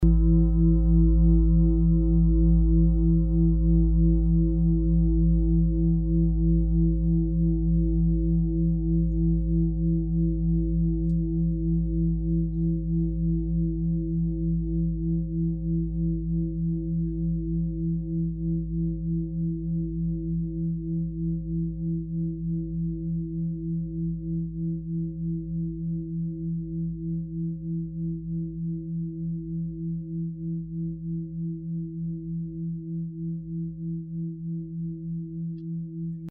Planetentonschale: Jupiter, Fuß-Reflexzonen Klangschale Nr.7, Erstes Chakra (Wurzelchakra) und Fünftes Chakra (Halschakra)
Fuß-Klangschale Nr.7
Klangschale-Durchmesser: 53,3cm
Sie ist neu und wurde gezielt nach altem 7-Metalle-Rezept von Hand gezogen und gehämmert.
Die Frequenz des Jupiters liegt bei 183,58 Hz und dessen tieferen und höheren Oktaven. In unserer Tonleiter ist das in der Nähe vom "Fis".
fuss-klangschale-7.mp3